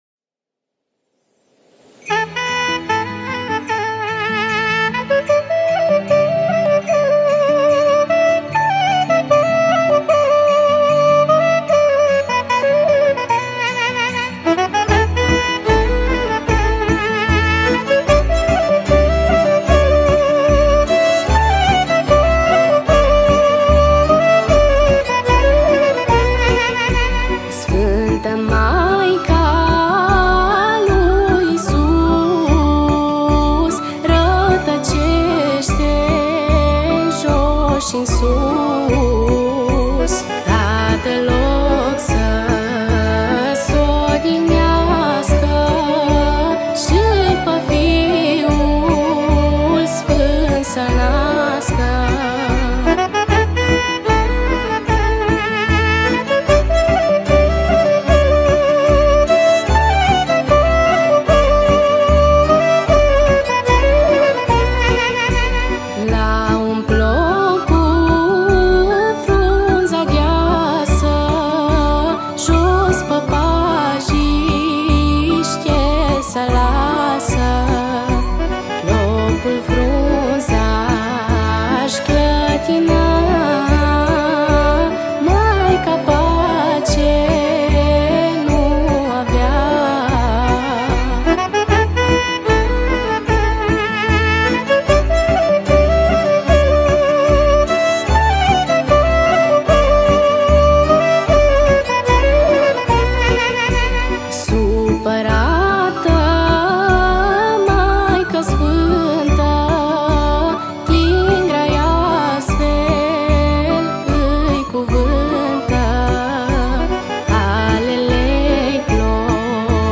Categoria: Colinde Craciun